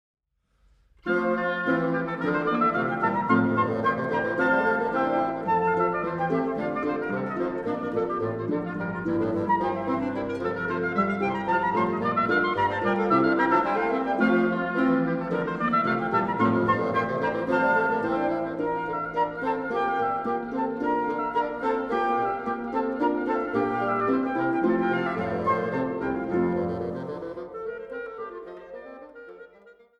Flöte
Oboe und Englischhorn
Klarinette
Fagott
Harfe